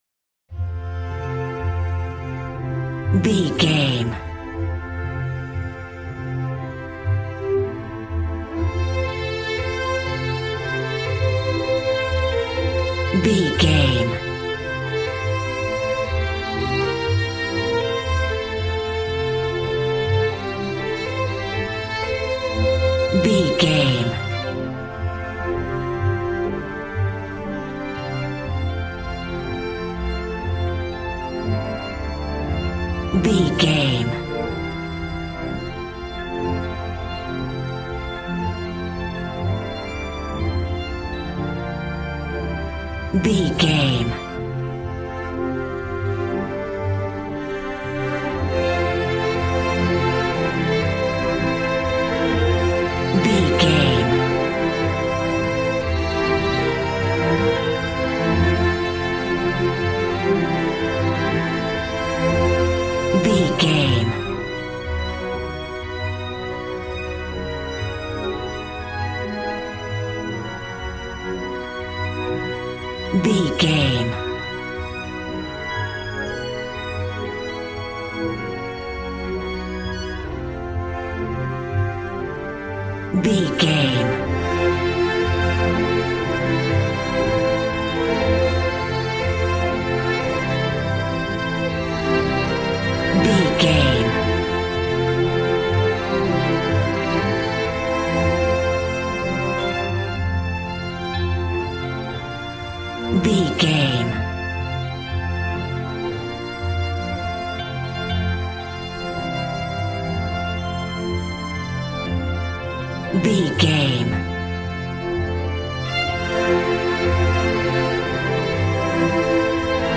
Ionian/Major
Fast
joyful
conga
80s